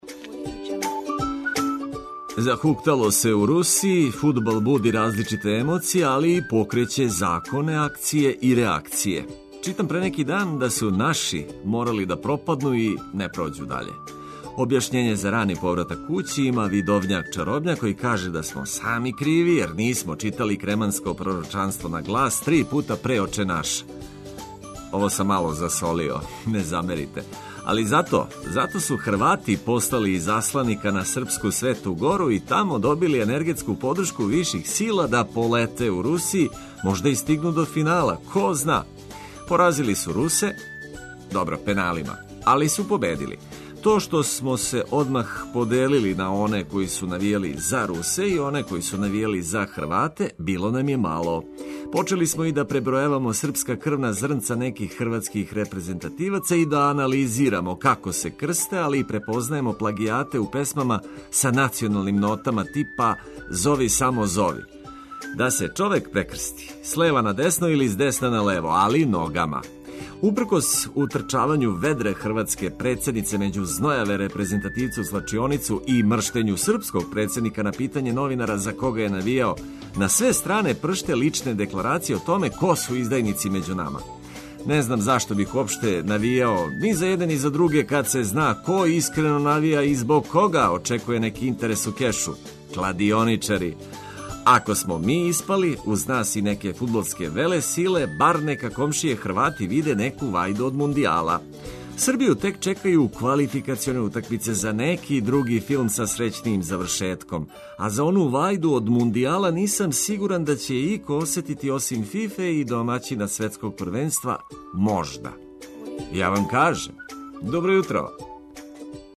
Понедељак и зашто га „волимо“ уз лепе приче и сјајну музику.